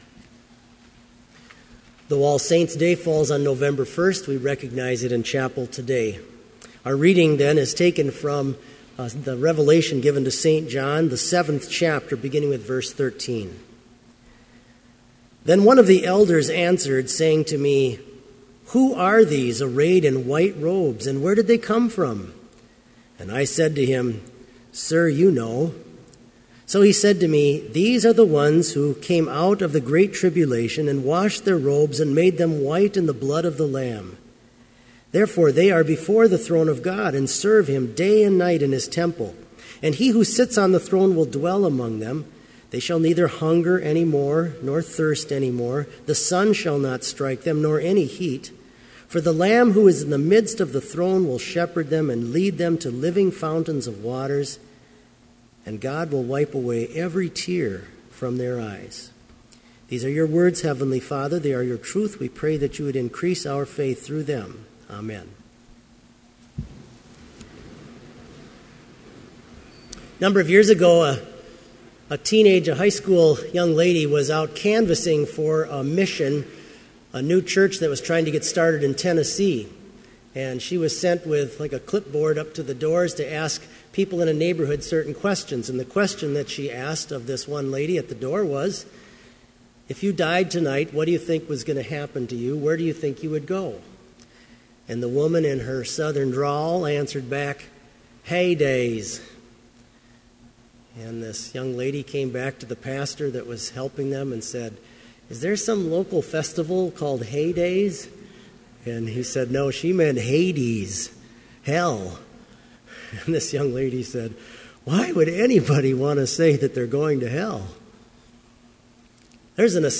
Complete Service
• Hymn 554, vv. 1-4, For All the Saints
• Homily
This Chapel Service was held in Trinity Chapel at Bethany Lutheran College on Monday, November 3, 2014, at 10 a.m. Page and hymn numbers are from the Evangelical Lutheran Hymnary.